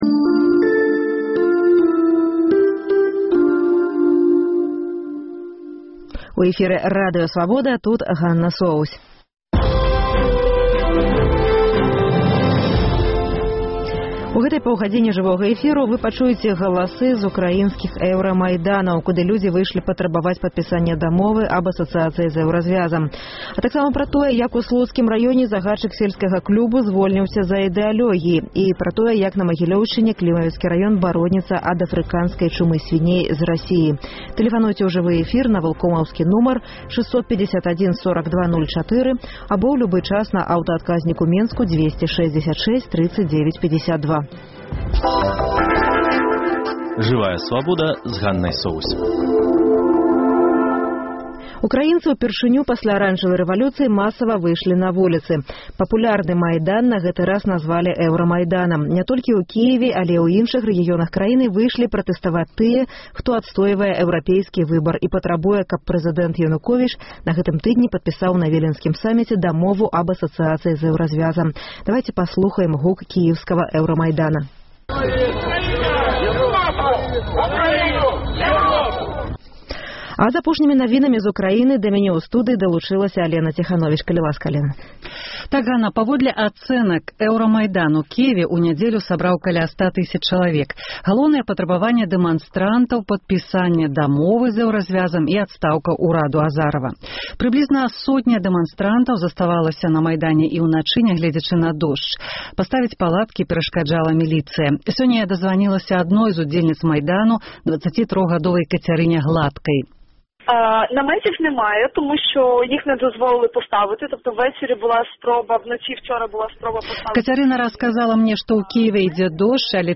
У нашай праграме самая апошняя інфармацыя з эўрамайданаў ва Ўкраіне і галасы беларусаў, якія бяруць удзел у пратэстах.